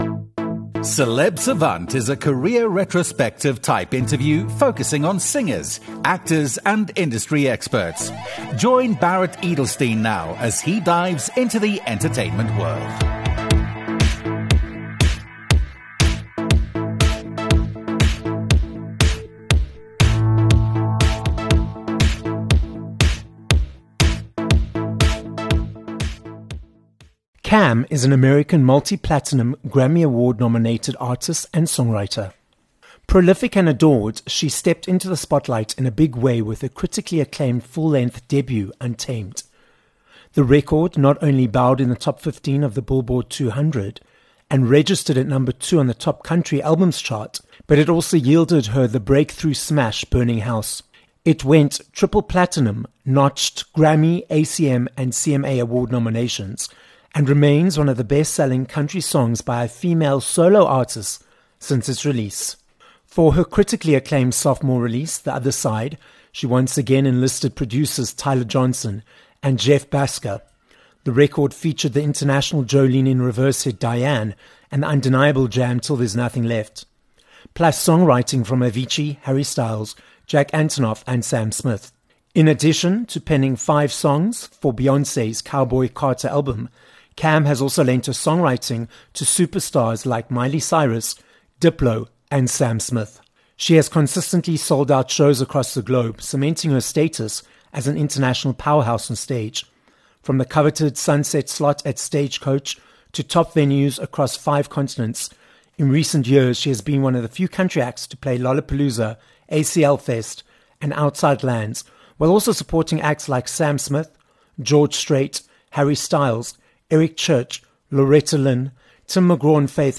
Cam - an American Grammy, ACM, CMA award nominated singer and songwriter - joins us on this episode of Celeb Savant. Cam explains how she kept going after being turned down by all the labels, until she was signed by Sony New York… and takes us on her multi-award nominated musical journey, which includes collaborating with Beyonce, Sam Smith, and Harry Styles. She also chats about her upcoming performance at The Cape Town Country Festival.